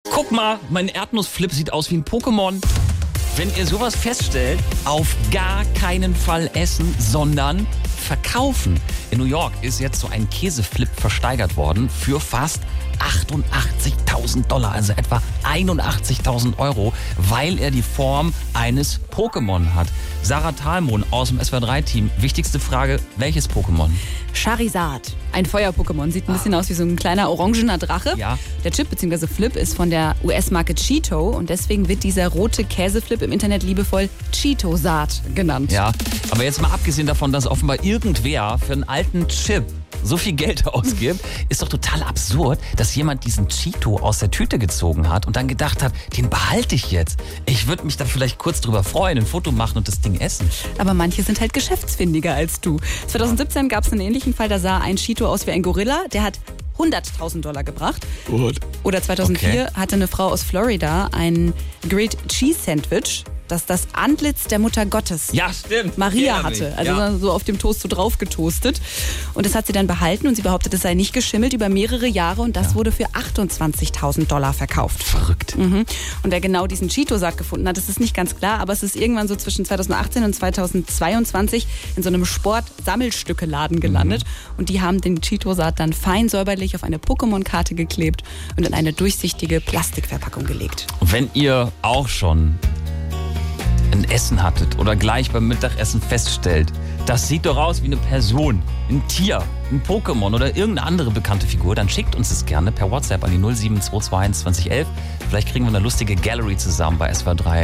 Ein Käsechip der US-amerikanischen Marke „Cheetos“ in Form der beliebten Figur Charizard aus Pokémon ist für 87.840 US-Dollar versteigert worden. In SWR3 Now wurden witzige Bilder von anderen Lebensmitteln gesammelt, die gerne etwas anderes wären.